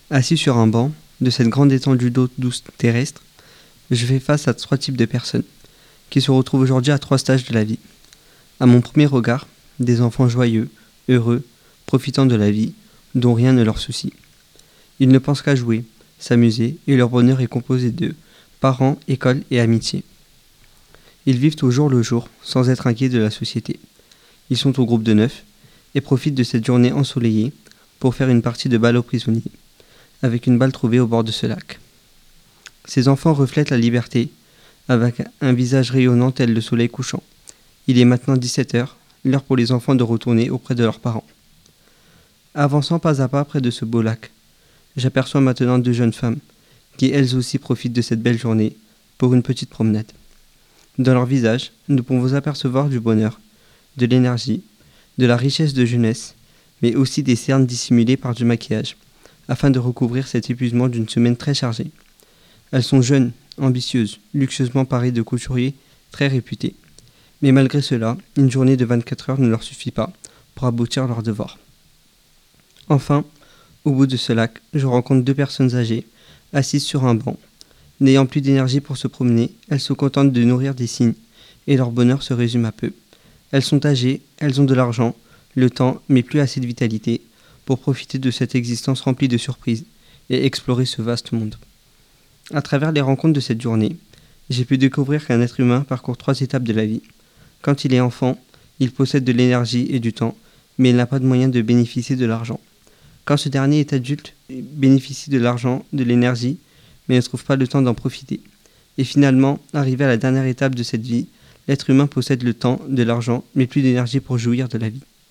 Une minute à Evry, en bord de lac
une_minute_a_evry_en_bord_de_lac.mp3